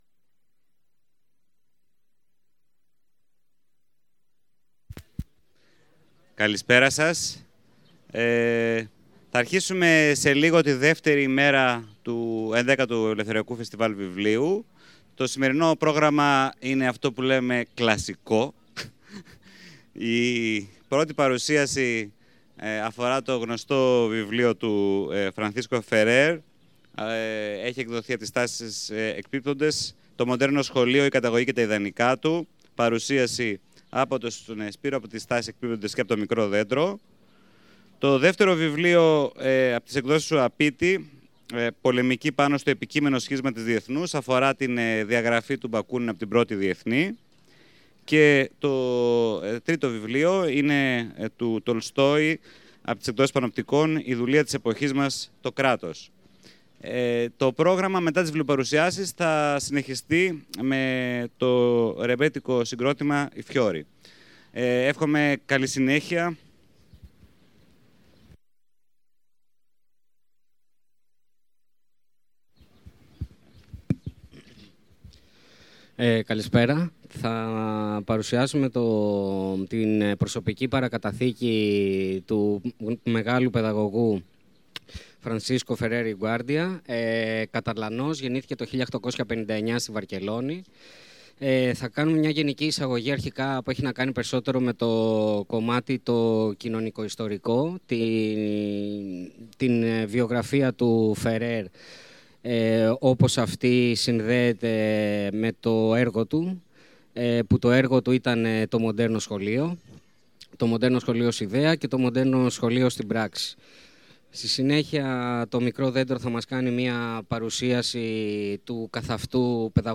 Ηχητικό αρχείο από τις εκδηλώσεις στα πλαίσια του 11ου Ελευθεριακού Φεστιβάλ Βιβλίου Θεσσαλονίκης, που πραγματοποιήθηκε στις 21-22-23 Ιούνη 2022 στην πάρκο στο Άγαλμα Βενιζέλου.